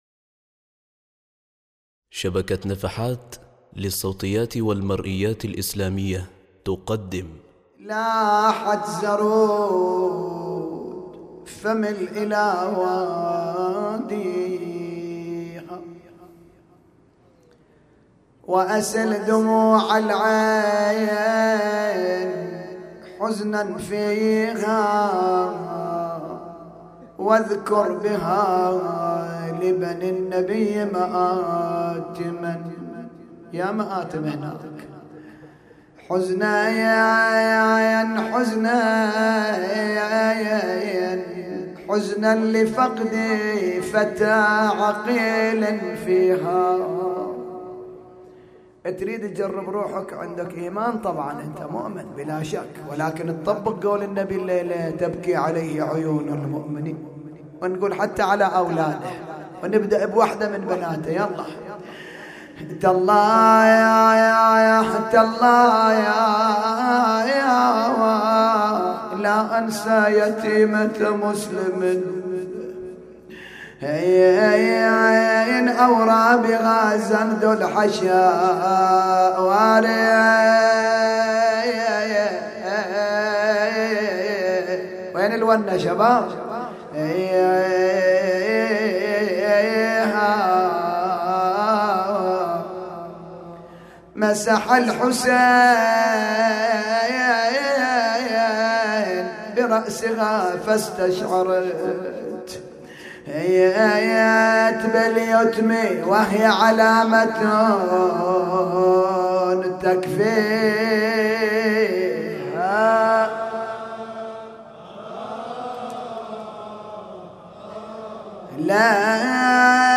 نعي ليلة 4 محرم 1439هـ |